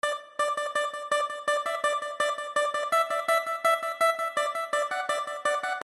Tag: 83 bpm RnB Loops Synth Loops 1.95 MB wav Key : Unknown